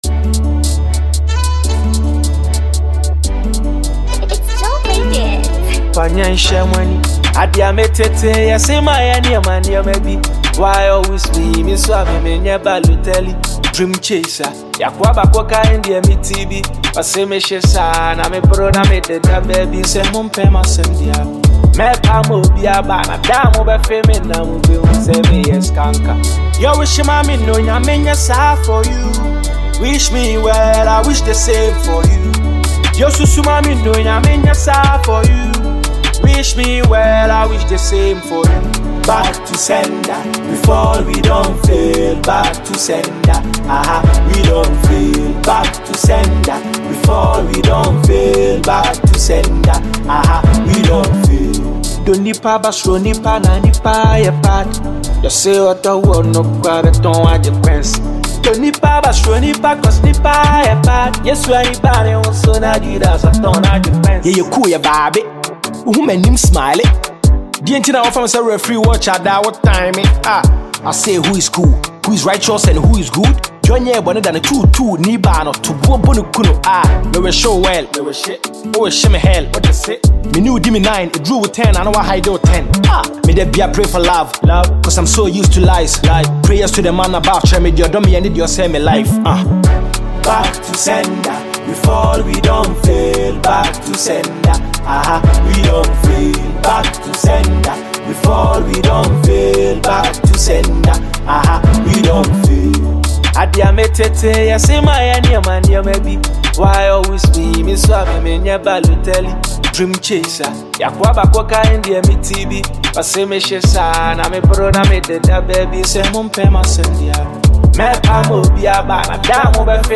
vocally soothing single